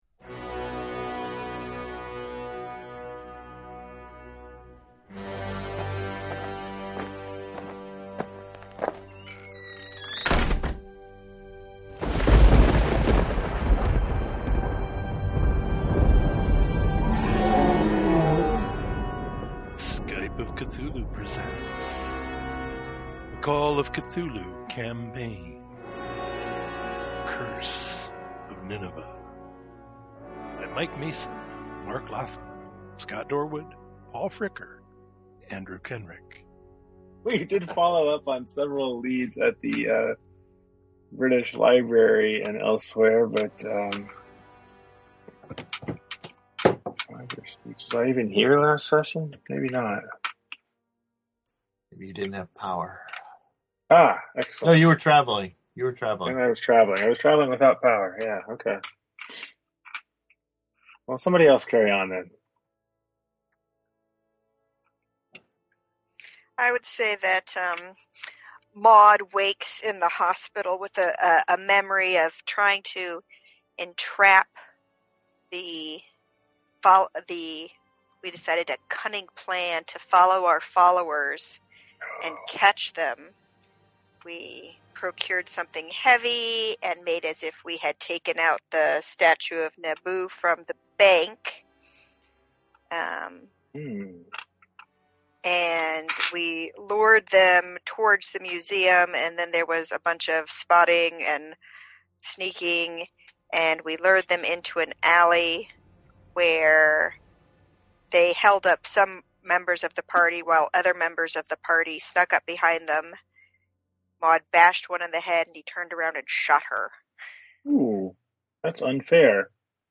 Call of Cthulhu, and perhaps other systems on occasion, played via Skype.